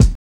HAT TIP.wav